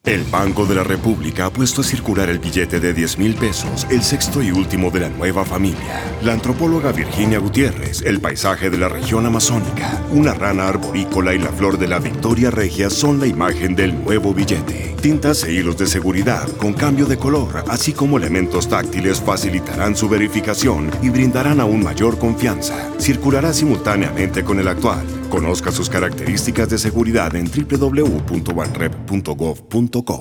Cuña radial
cuna_radio-10_1.wav